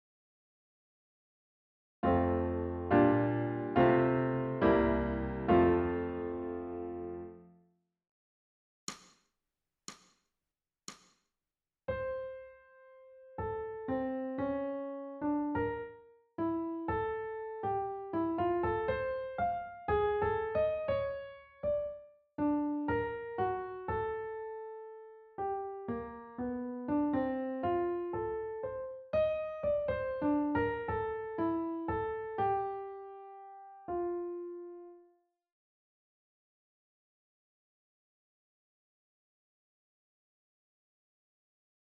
ソルフェージュ 聴音: 2-1-41